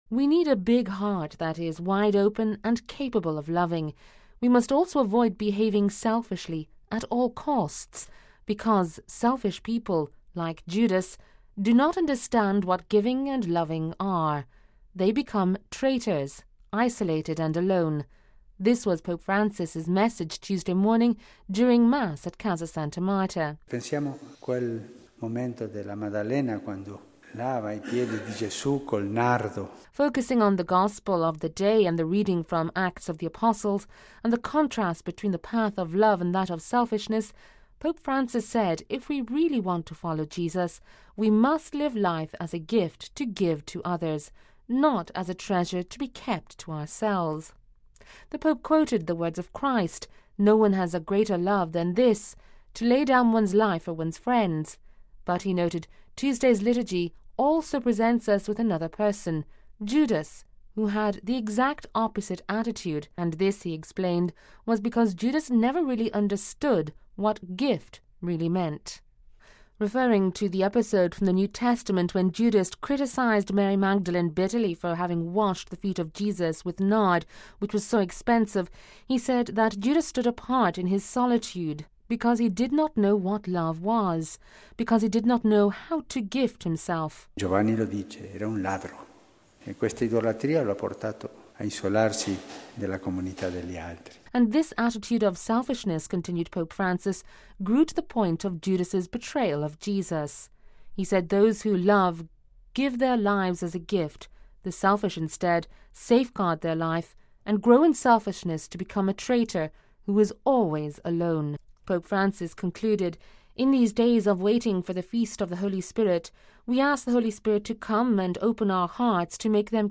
We must also avoid behaving selfishly at all costs because, selfish people, like Judas, do not understand what giving and love are; they become traitors, isolated and alone. This was Pope Francis’ message Tuesday morning during Mass at Casa Santa Marta.